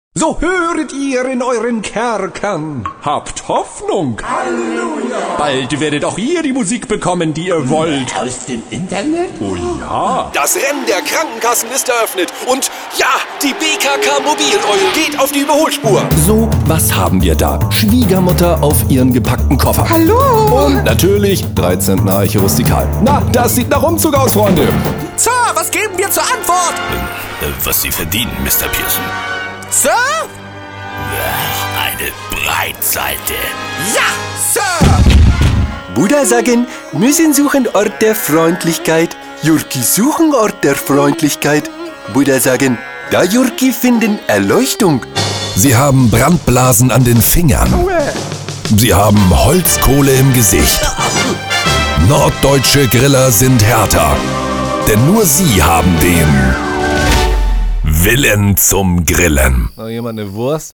deutscher Sprecher
norddeutsch
Sprechprobe: eLearning (Muttersprache):
german voice over artist